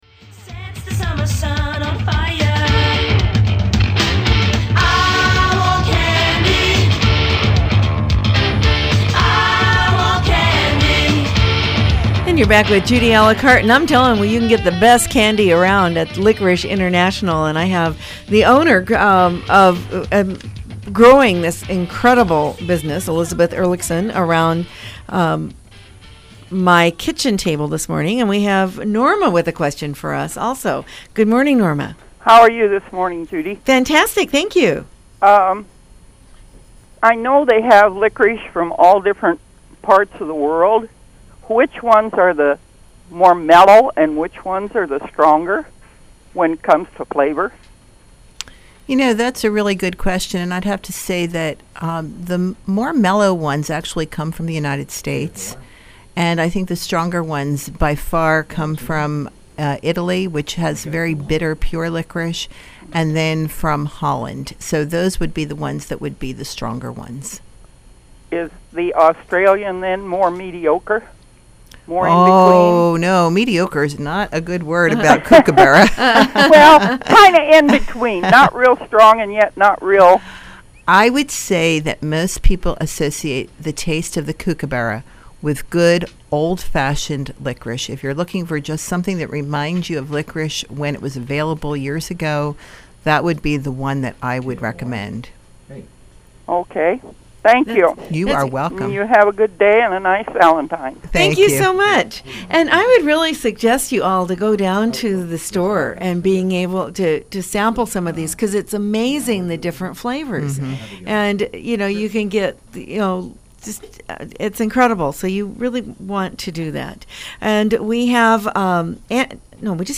To listen to the program (in four segments), simply click on the "play" button for each of the segments.